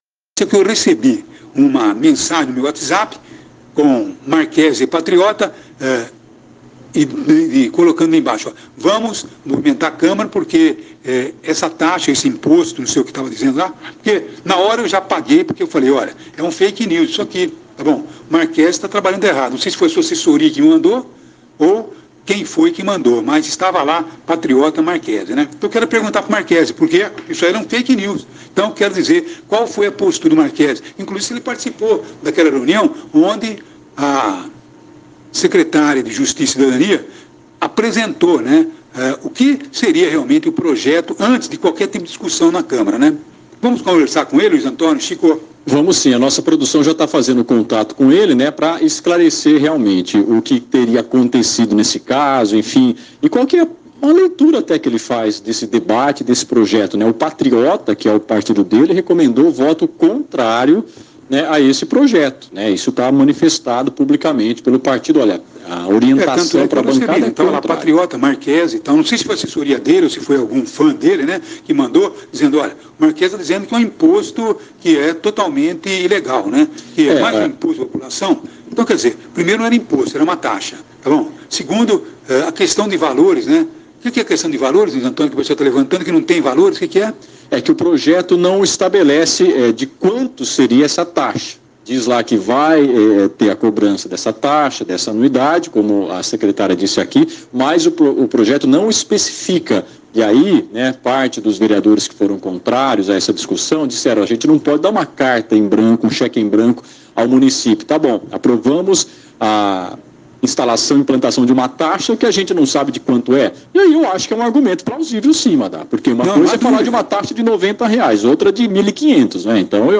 Durante programa de Rádio, o vereador Marchese da Rádio (Patriota) e o radialista discutiram por conta do Projeto de Lei Complementar 3/2022, que versa sobre a instalação, a organização, o funcionamento dos cemitérios e dos crematórios no Município, proposto pelo Prefeito Edinho Silva.